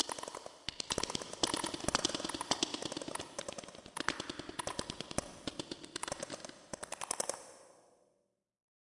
描述：Small field of short and delicate noise events developed from the ticking sound of a chiming clock.
标签： noise reverberation repetitions
声道立体声